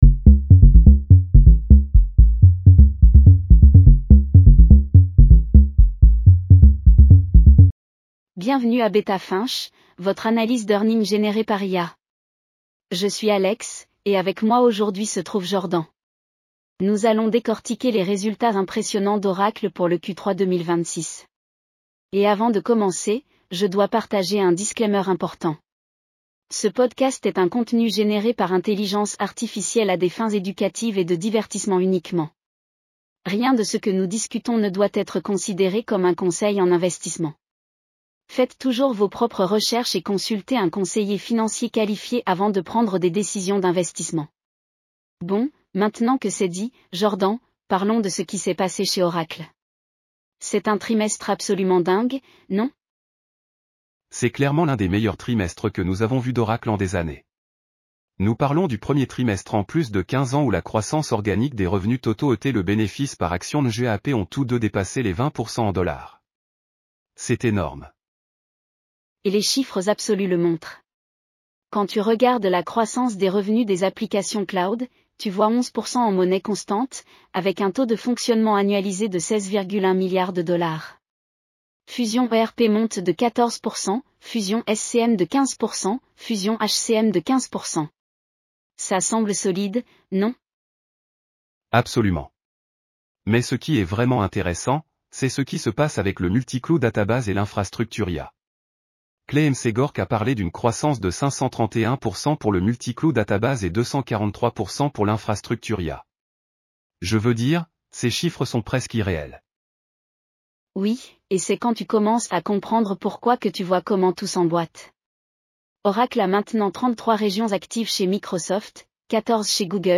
AI-powered earnings call analysis for Oracle (ORCL) Q3 2026 in Français.